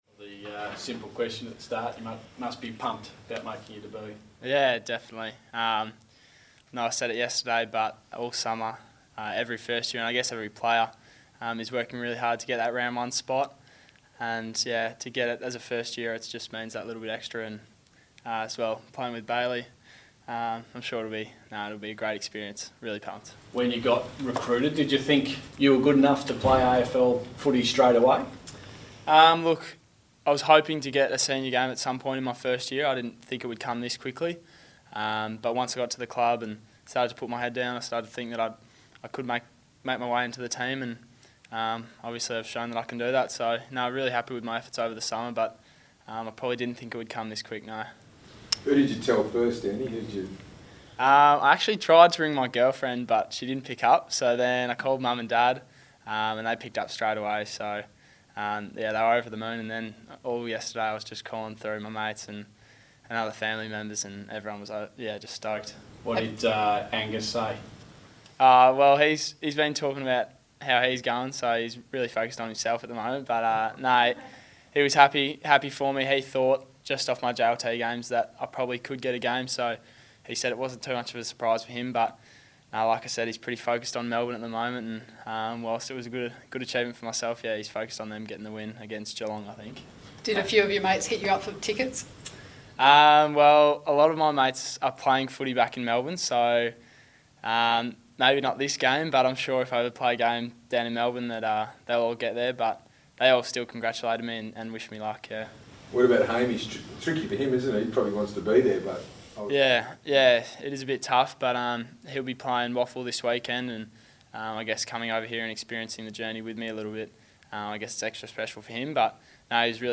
Andy Brayshaw media conference - 20 March